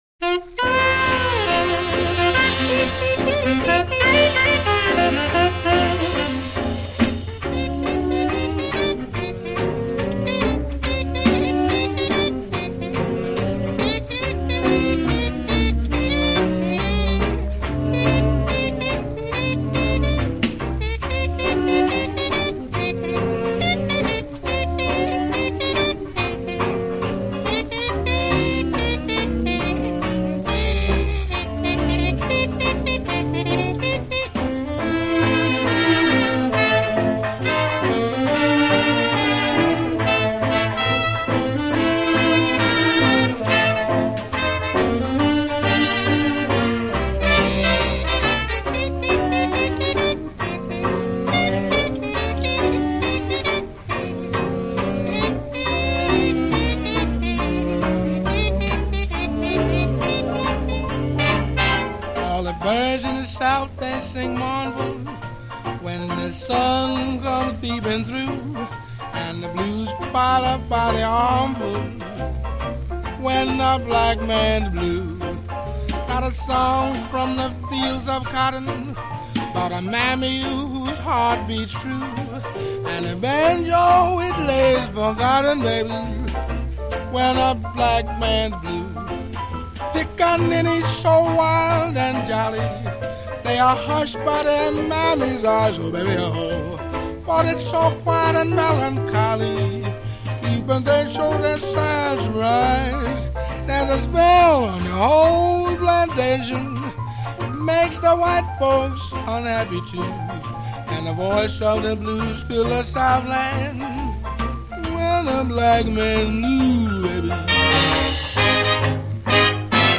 Maar de zanger laat zich er niet door ontmoedigen.